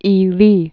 (ēlē)